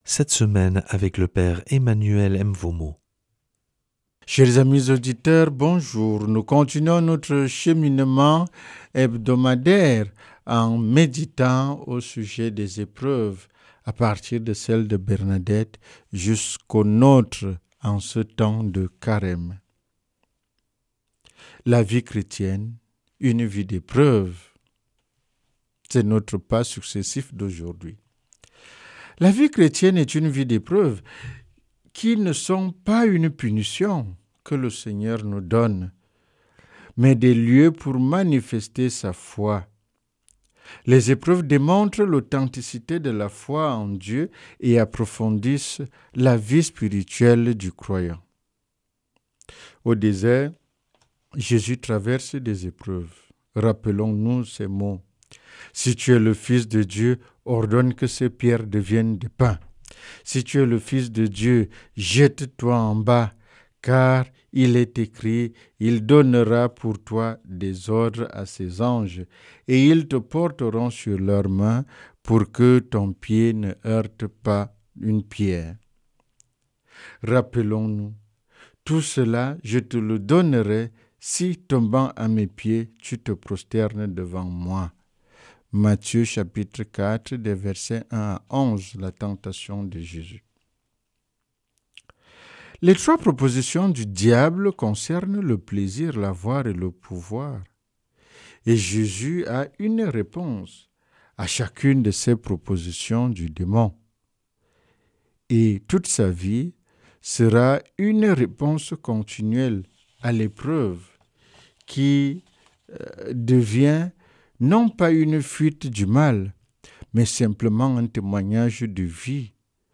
mercredi 4 mars 2026 Enseignement Marial Durée 10 min
Une émission présentée par